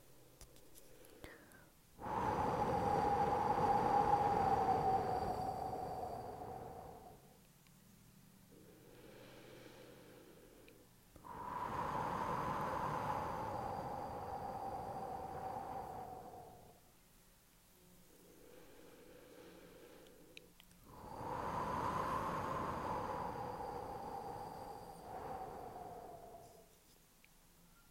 Third Sound: HU
Similarity: like blowing on hot soup to cool it off